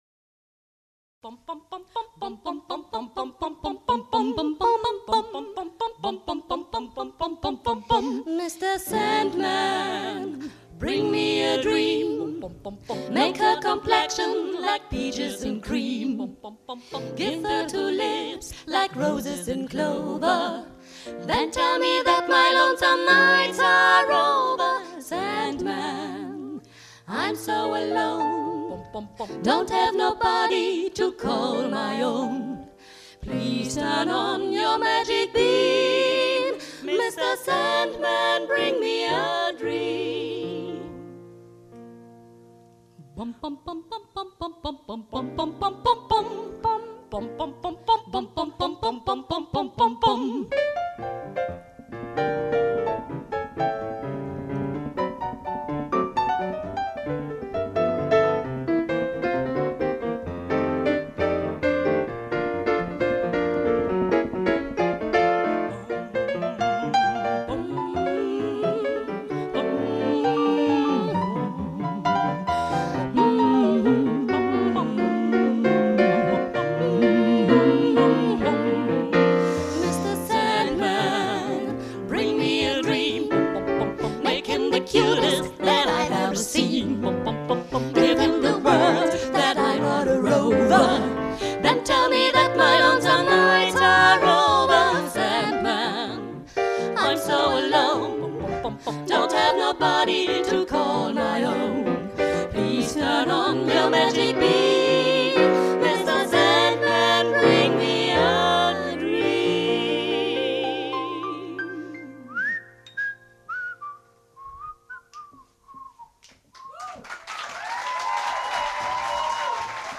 Konzertmitschnitt